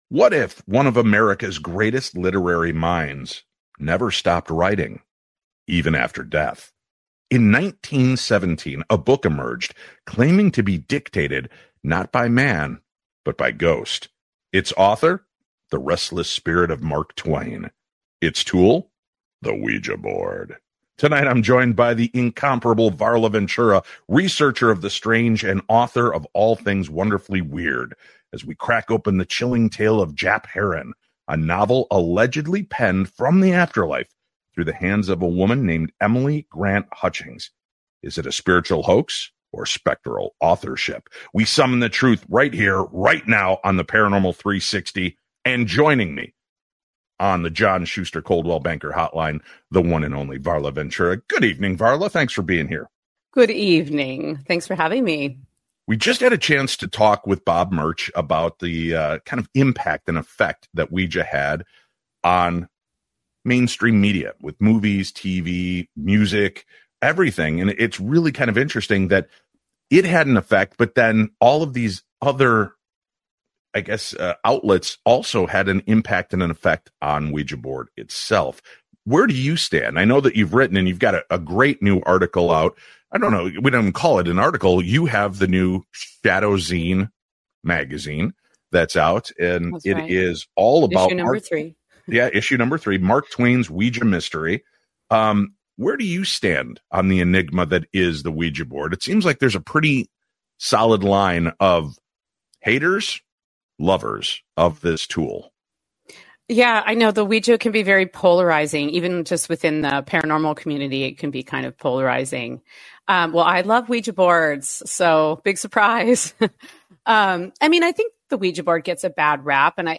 Each episode offers a deep dive into the mysterious, the eerie, and the unexplainable, featuring interviews with experts and firsthand accounts that will leave you questioning the boundaries of reality.